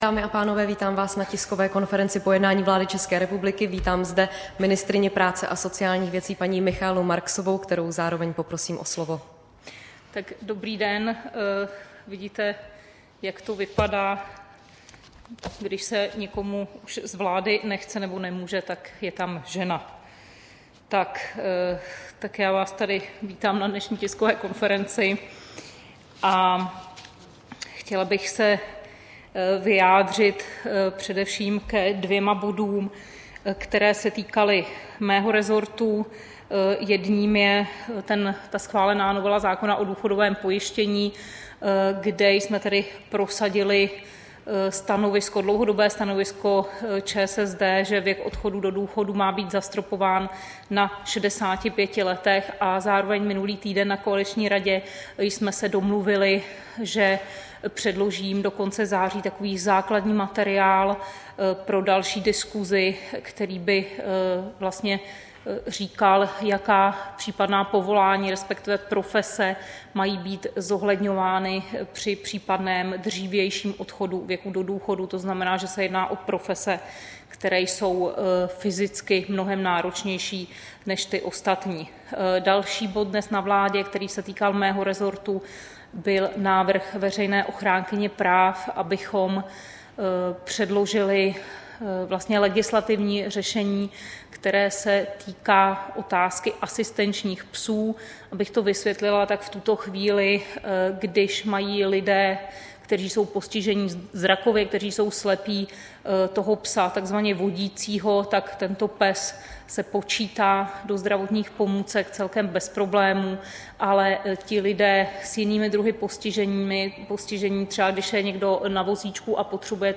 Tisková konference po jednání vlády 5. září 2016